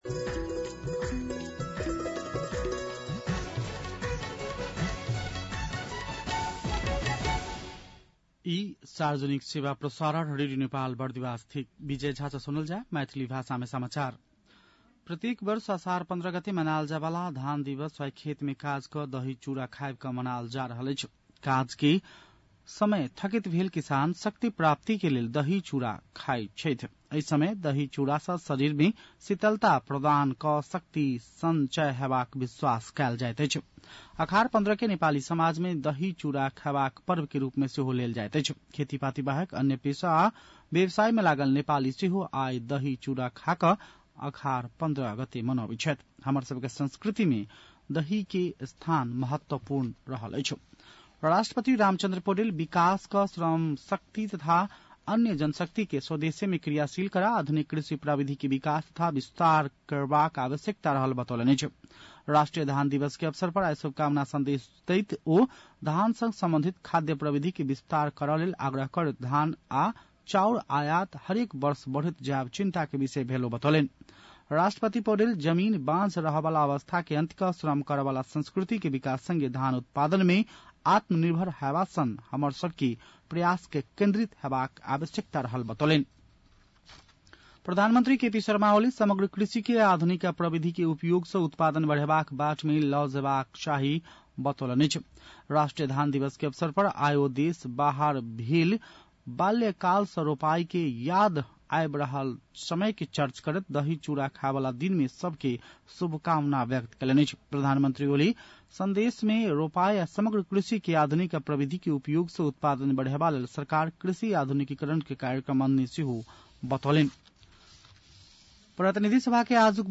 मैथिली भाषामा समाचार : १५ असार , २०८२
6.-pm-maithali-news-1-3.mp3